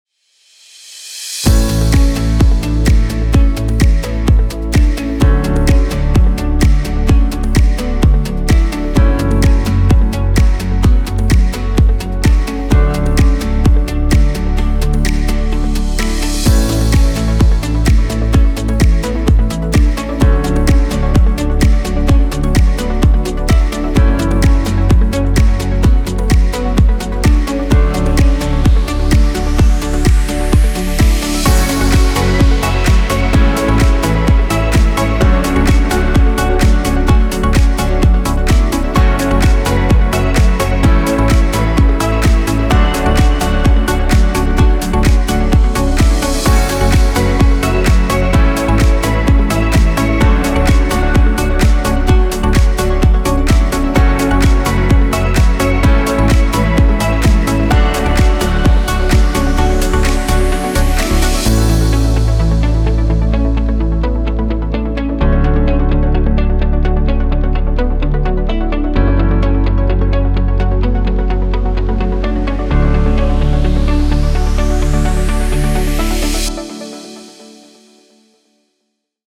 Ringtone